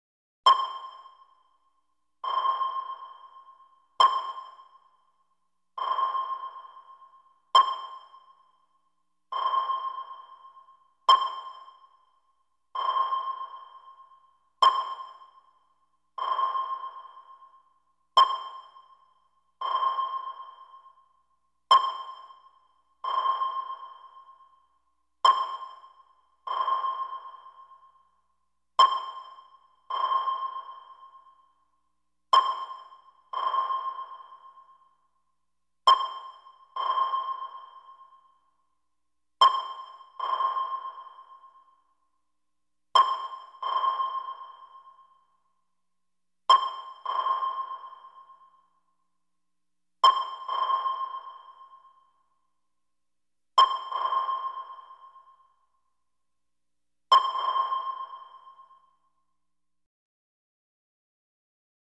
Звуки сонара
Звук подводной лодки с работающим сонаром в глубинах океана